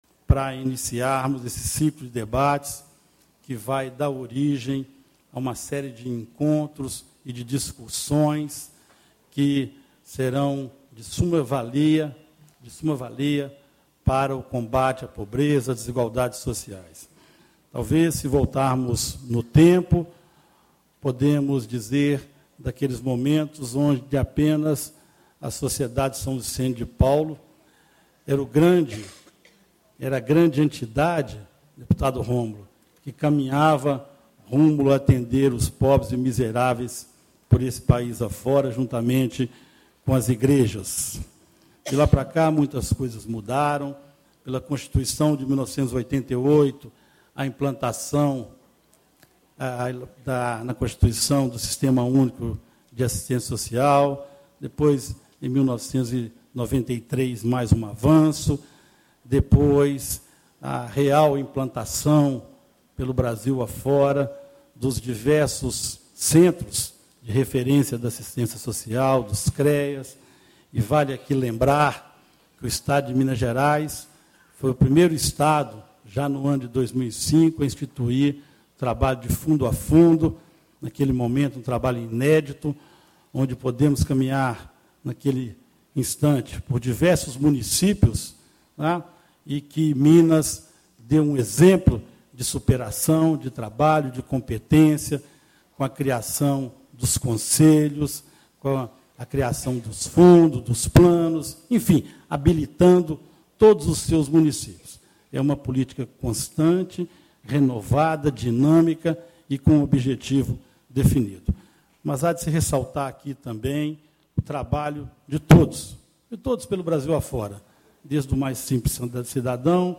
Ciclo de Debates Estratégias para Superação da Pobreza
Discursos e Palestras